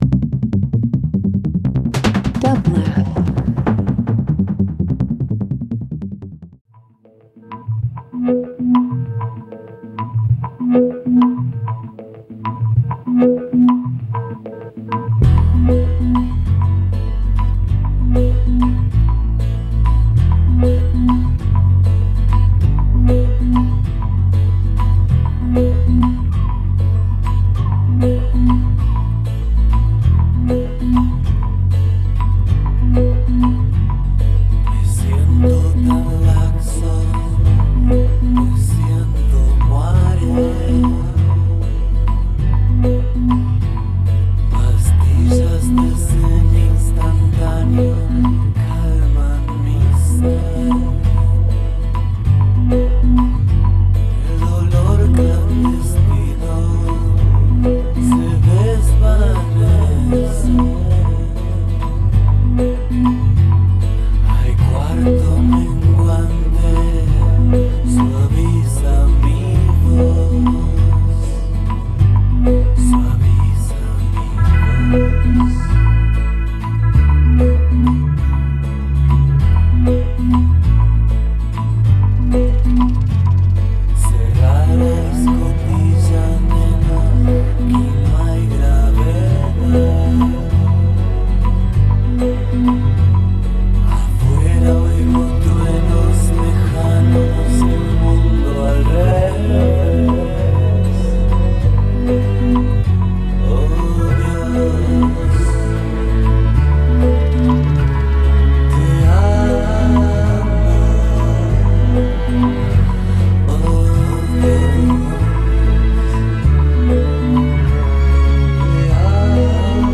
Alternative Ambient Dance Electronic